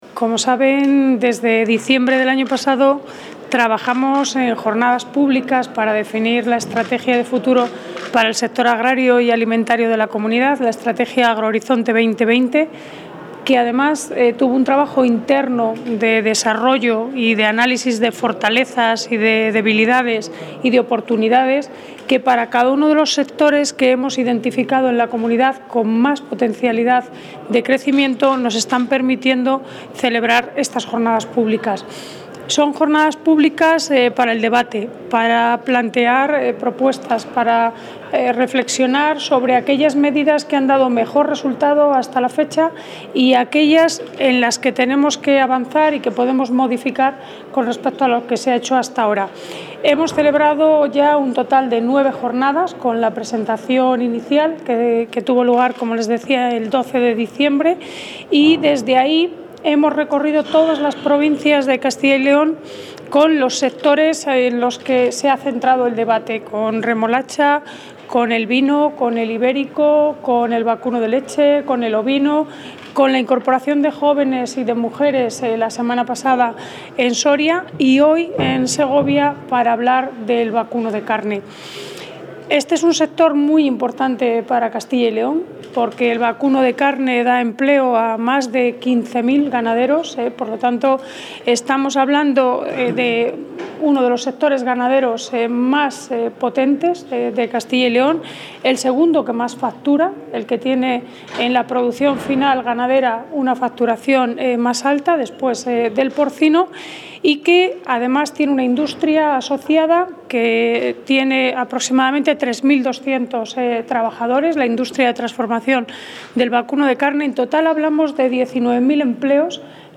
Audio consejera.
La consejera de Agricultura y Ganadería, Silvia Clemente, ha clausurado la jornada dedicada al vacuno de carne celebrada en el marco de ‘AgroHorizonte 2020’, estrategia global del sector agrario para los próximos años. En esta jornada de debate, celebrada en Segovia, se ha analizado la situación actual del sector y las perspectivas de futuro. La consejera ha anunciado la puesta en marcha de medidas destinadas a incrementar la productividad de las explotaciones e incrementando los animales cebados en las propias explotaciones, aprovechando que la nueva PAC prima esta práctica.